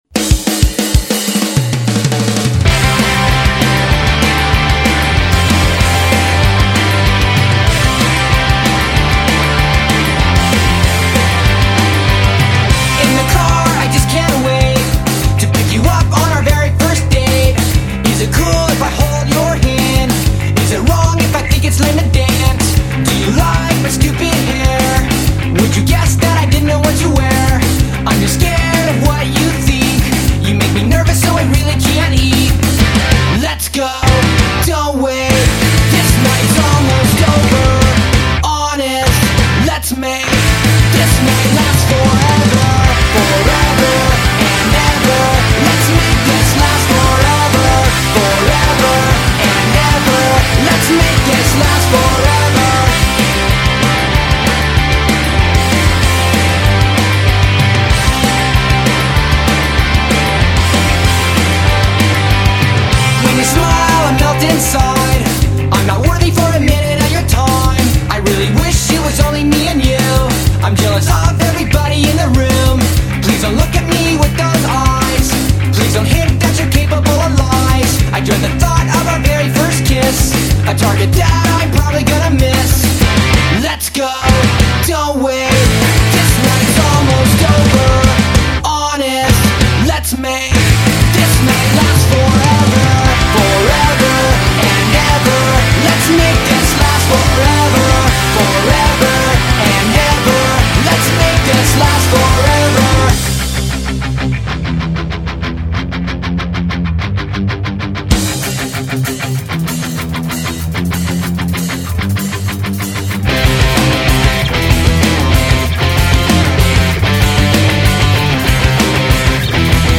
a really melodic punk, speed and very fun.
There was the same energy and the same fun.
I love Punk